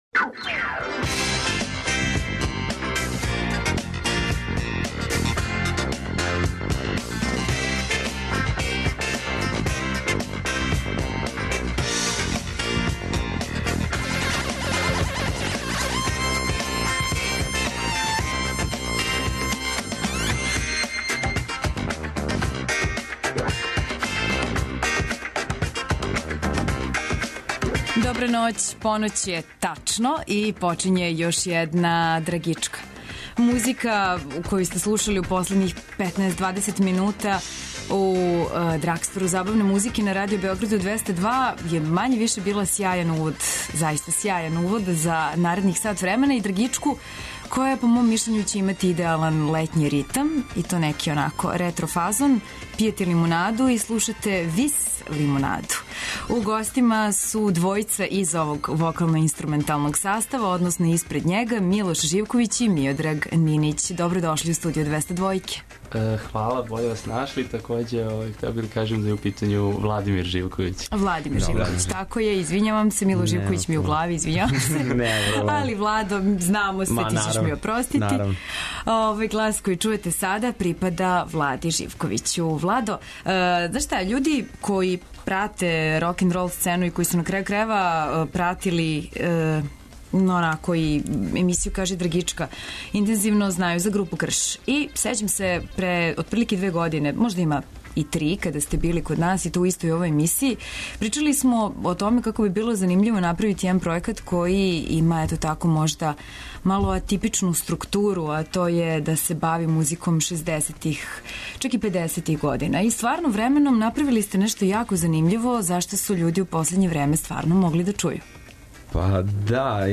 Вечерас нам у госте долази 'ВИС Лимунада'. По префиксу 'ВИС' јасно је да ћемо ноћас по поноћи бити у ретро, вокално-инструменталном фазону.